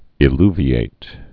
(ĭ-lvē-āt)